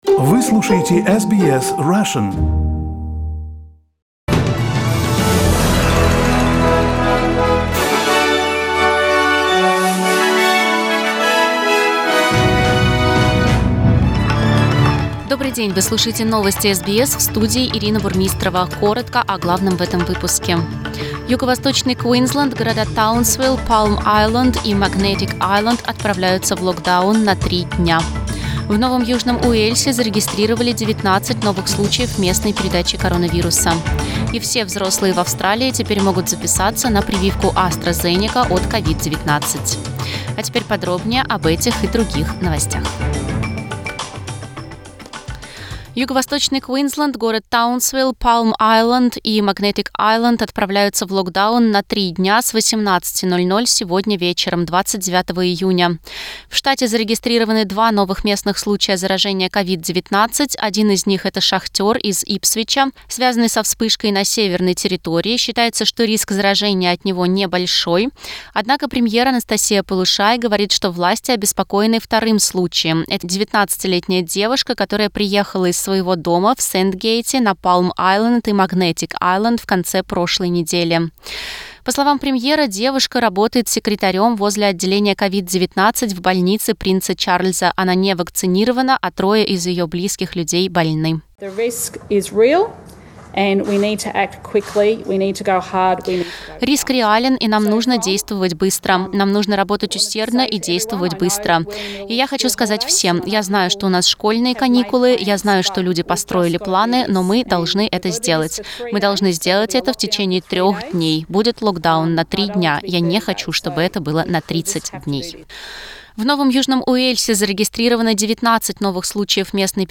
Новости SBS на русском языке - 29.06